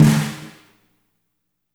-FAT SNR1T-L.wav